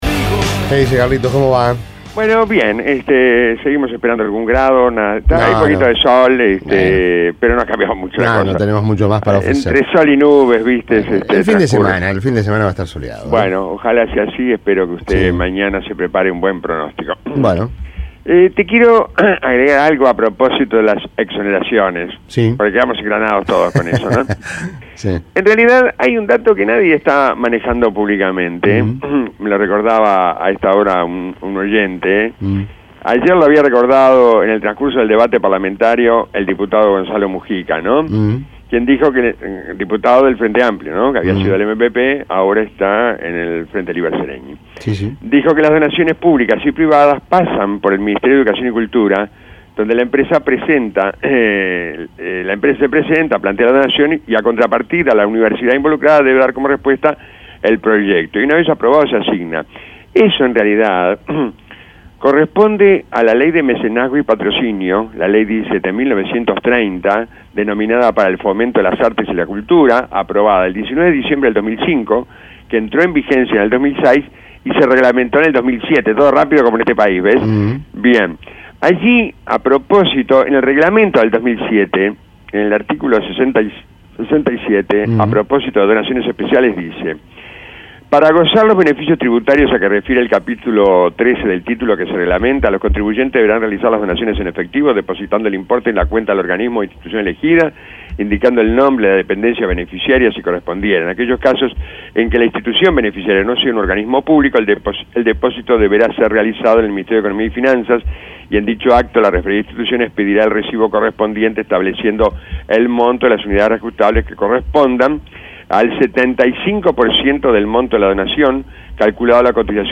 La columna